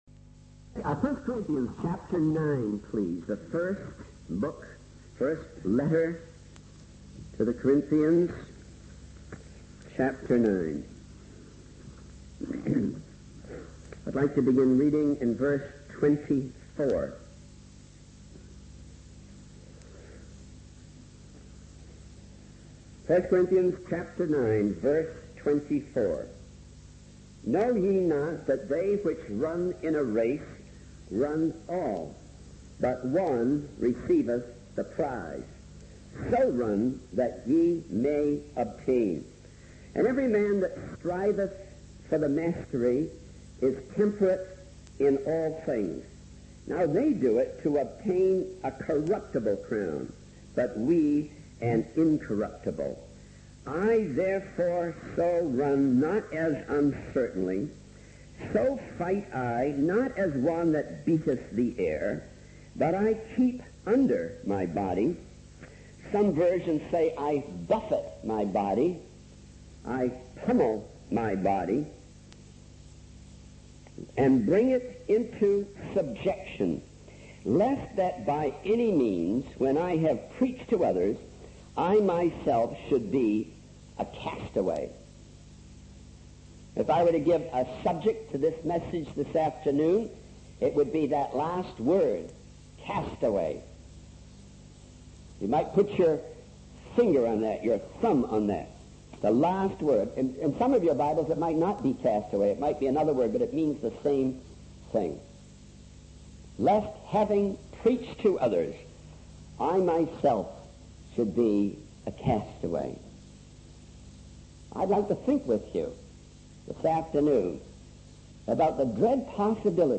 In this sermon on 1 Corinthians chapter 9, the preacher focuses on the concept of running the race of faith and obtaining the prize. He emphasizes the importance of discipline and self-control in striving for spiritual mastery. The preacher highlights the contrast between the corruptible crowns sought after in worldly races and the incorruptible crown promised to believers.